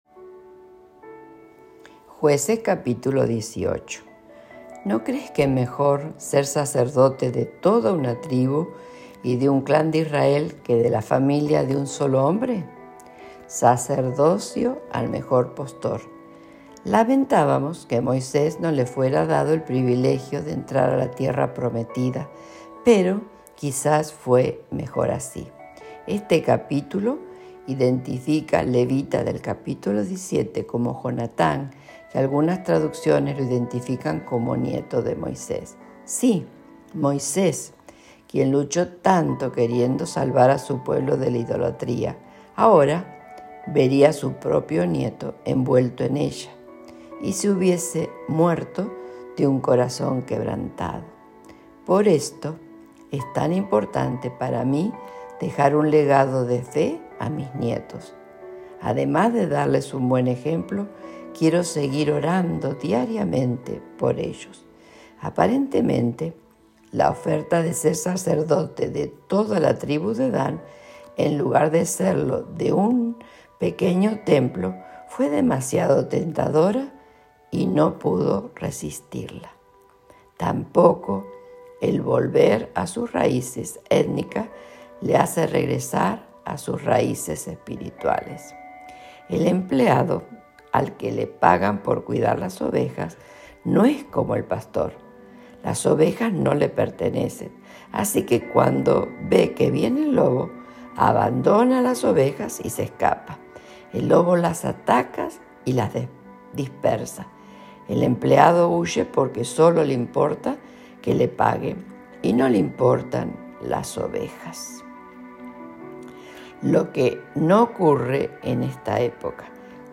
Reflexión